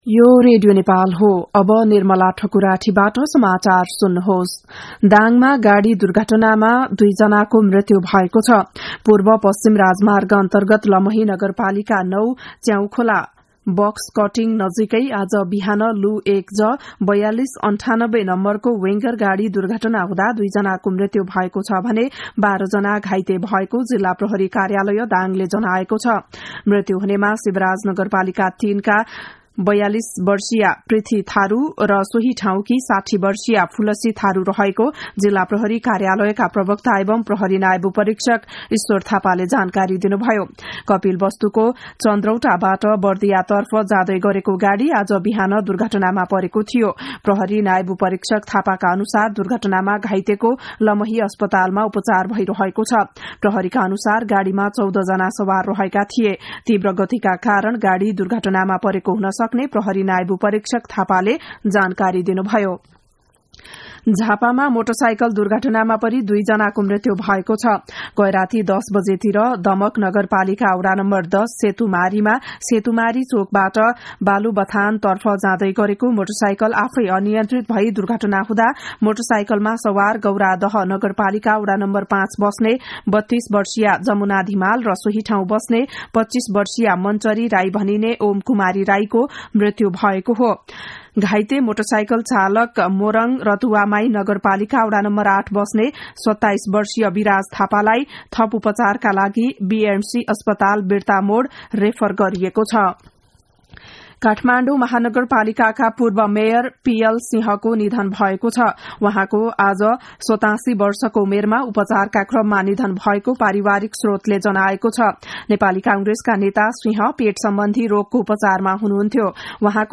बिहान ११ बजेको नेपाली समाचार : २ पुष , २०८१
11-am-nepali-news-1-13.mp3